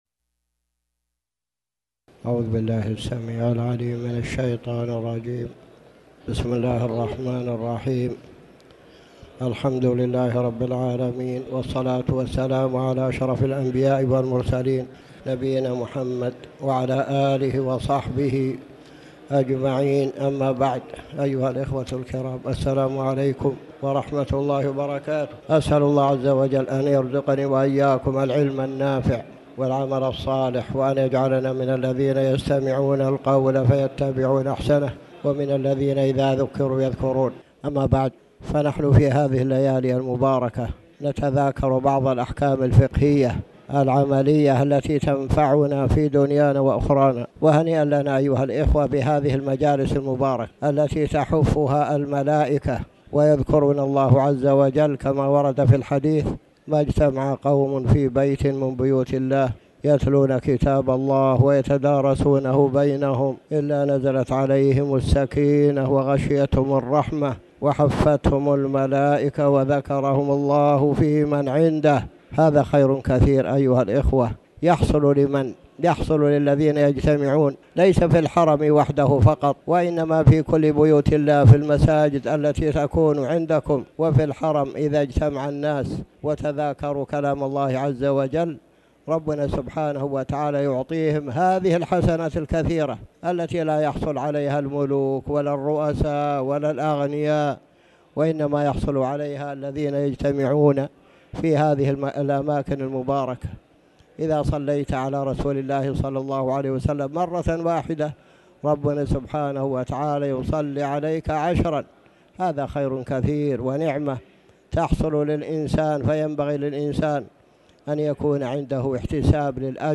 تاريخ النشر ٧ جمادى الأولى ١٤٣٩ هـ المكان: المسجد الحرام الشيخ